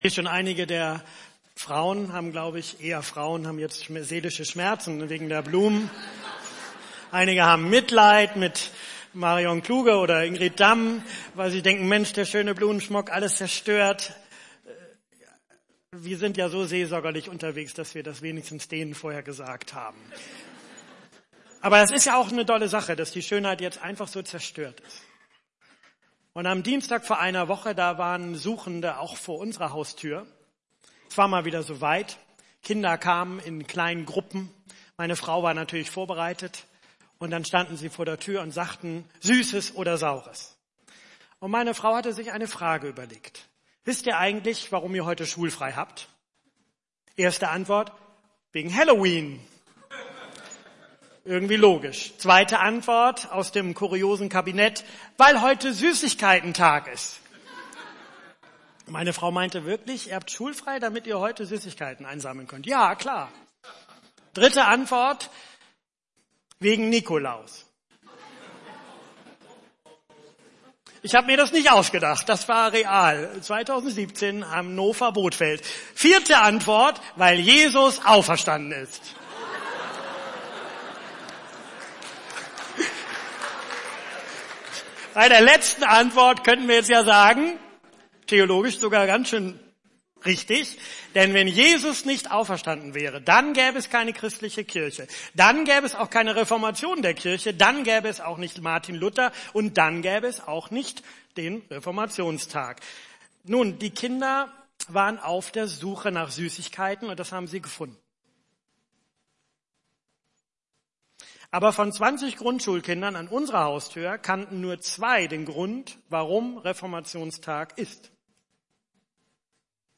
Predigttext: Matthäus 28, 16-20